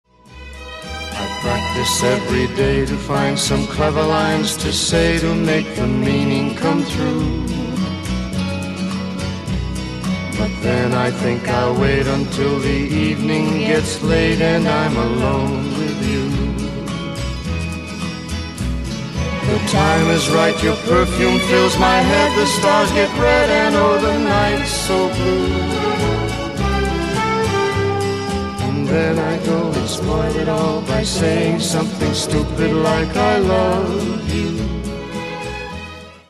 • Качество: 128, Stereo
поп
мужской вокал
женский вокал
ретро